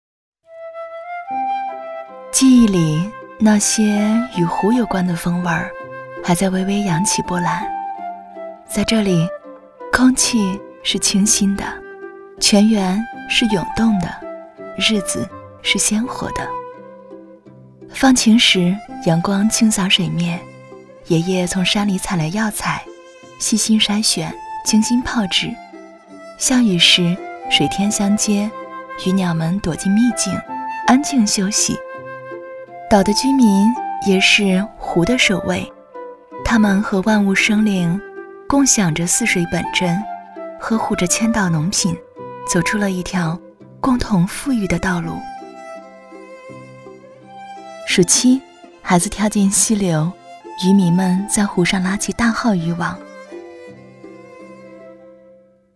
女267-短视频—千岛农品
女267专题广告解说彩铃 v267
女267-短视频-千岛农品.mp3